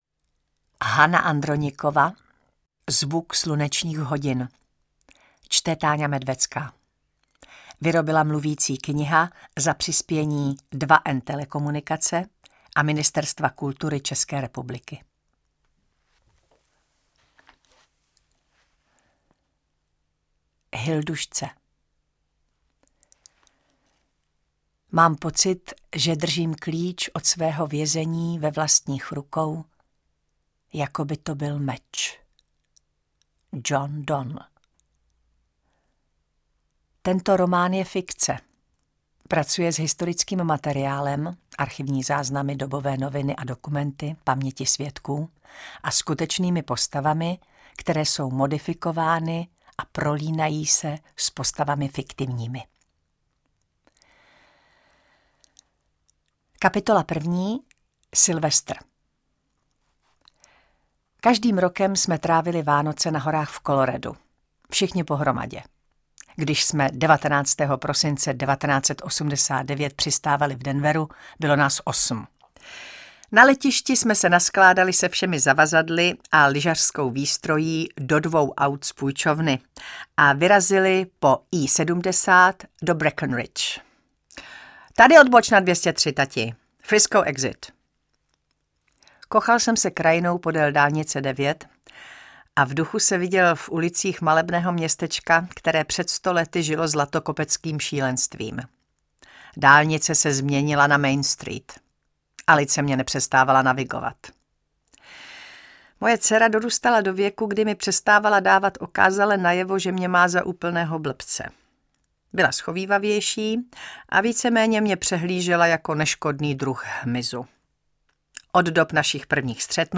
Čte: Táňa Medvecká